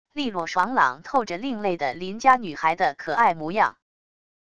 利落爽朗透着另类的邻家女孩的可爱模样wav音频生成系统WAV Audio Player